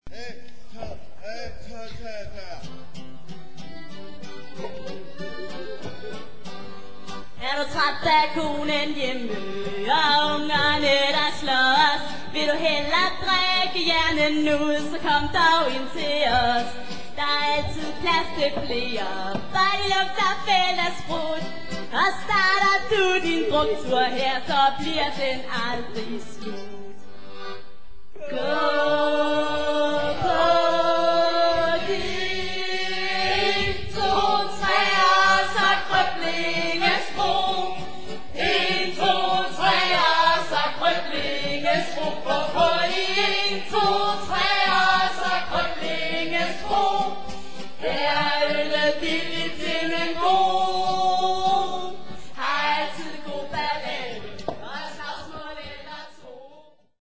Vi hører det ene store svingende nummer efter det andet.
"live on stage!"
Drikkevise